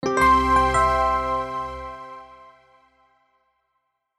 Victory SoundFX1.wav